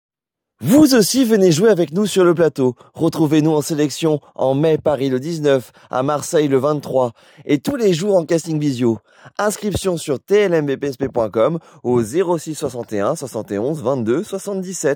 Voix off TLMVPSP test rapide
- Baryton